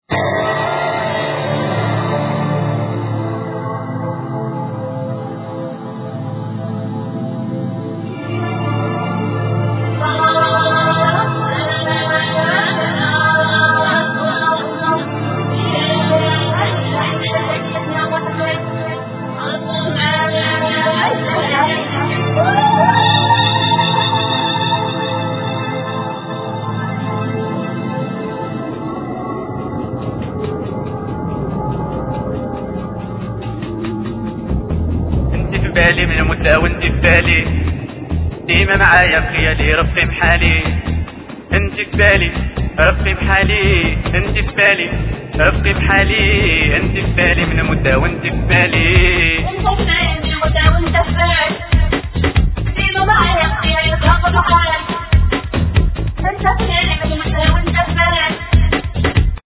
Voclas, Oud, Darbukka
Violin
Keyboards
Background Vocals
Bass
Drums